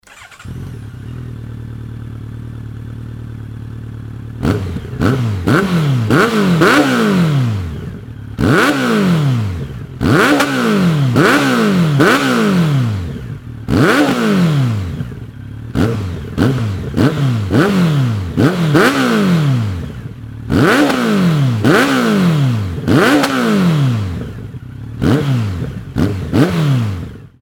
排気音量（アイドリング）は結構な大きさなので
ザ･4気筒エンジンという感じで
キメの細かさと1300ccの力強さが融合した
空吹かしの排気音を収録してきたのでどうぞ･･
CB1300の排気音